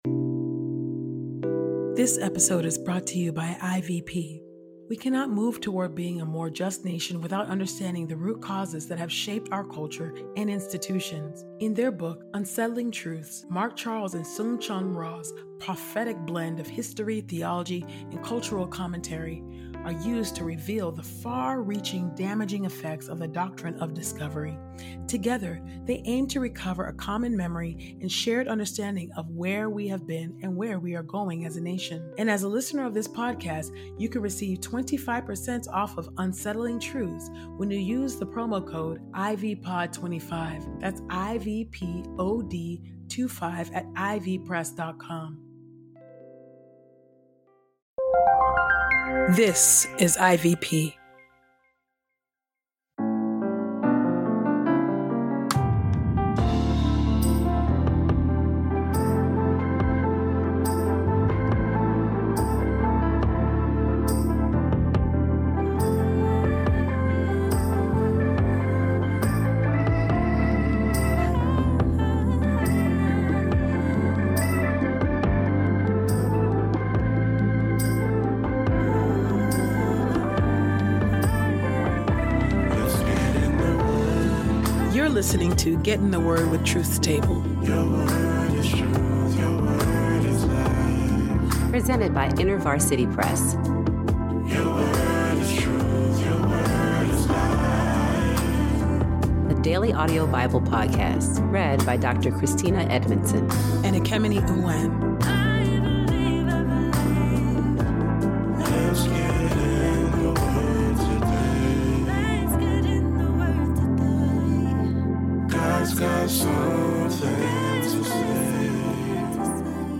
Our Bible reading plan is adapted from Bible Study Toget…